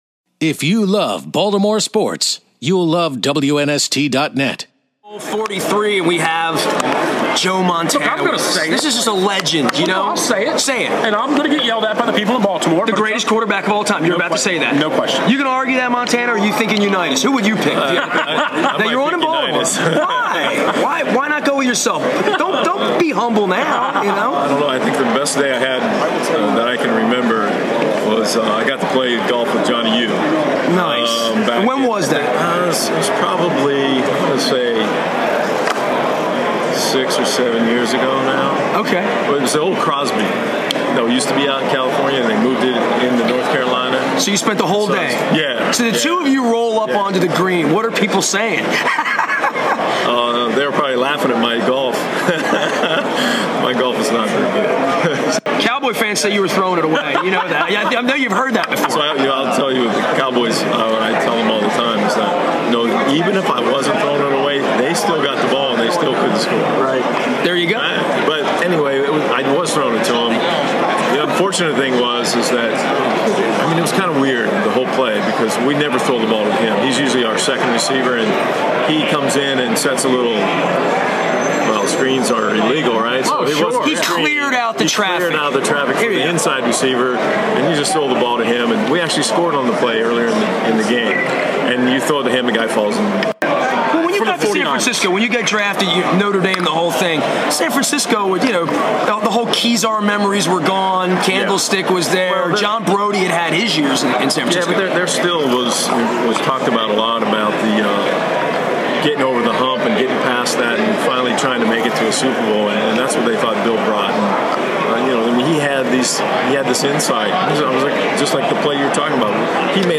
Joe Montana Tampa Super Bowl Radio Row 2009